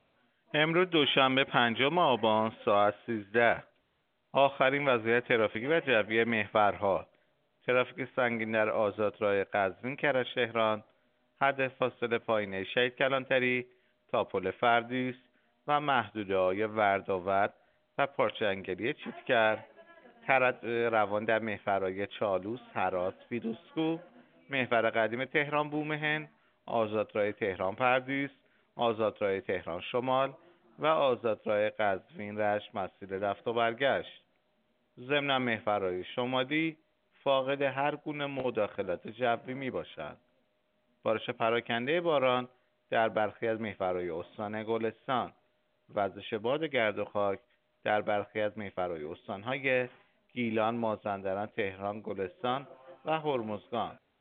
گزارش رادیو اینترنتی از آخرین وضعیت ترافیکی جاده‌ها ساعت ۱۳ پنجم آبان؛